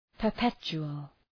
Προφορά
{pər’petʃu:əl}